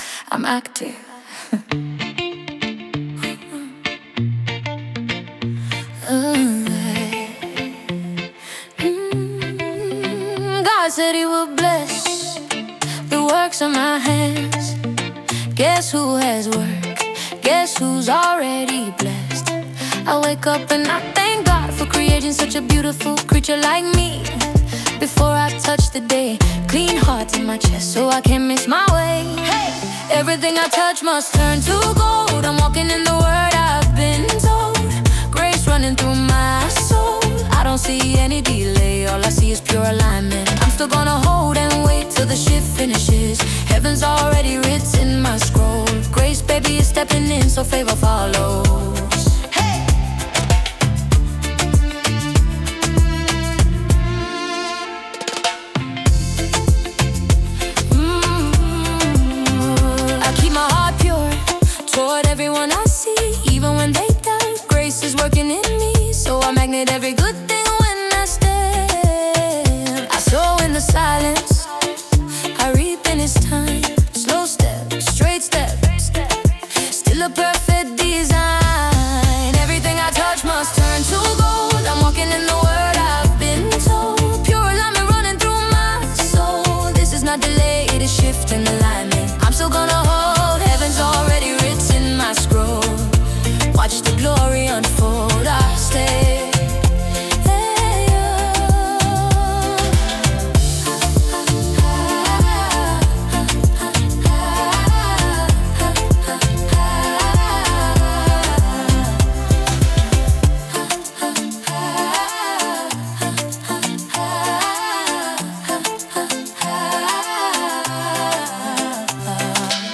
Home » gospel
There is a calm presence in how this song unfolds.
As it plays, the tone feels more grounded.